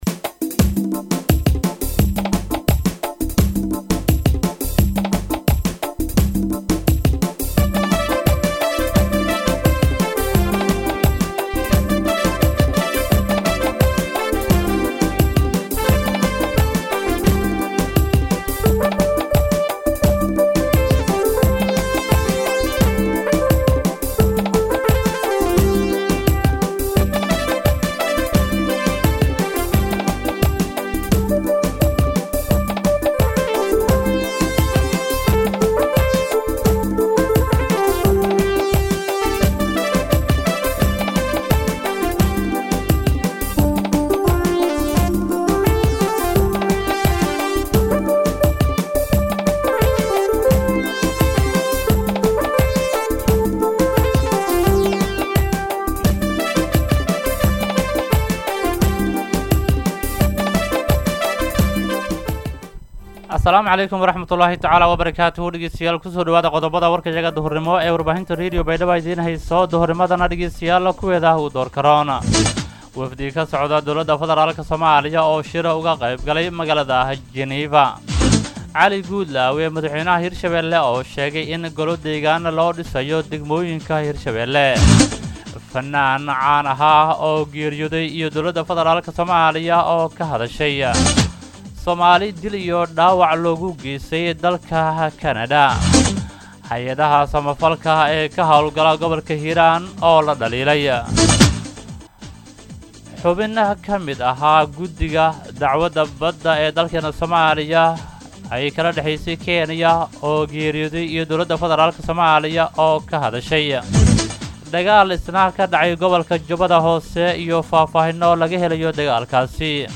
DHAGEYSO:-Warka Duhurnimo Radio Baidoa 2-9-2021